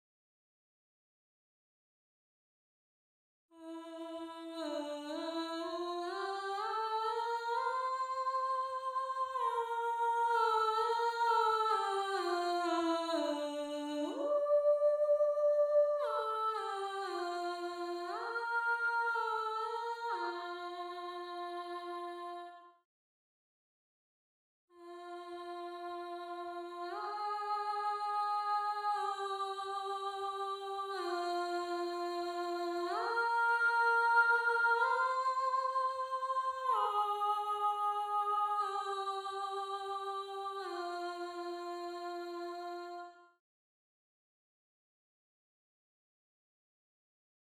Voice 3 (Alto/Alto)
gallon-v8sp5-21-Alto_0.mp3